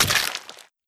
amogus_kill.wav